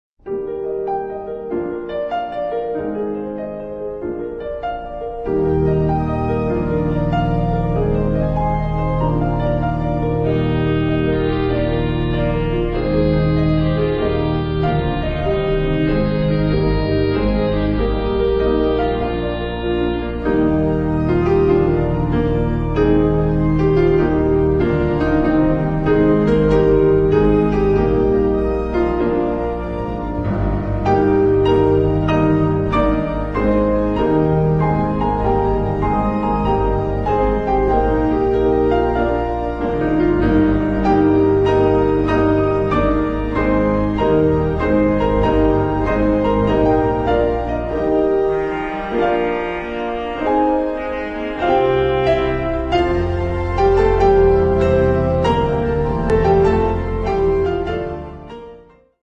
Voicing: Piano and Organ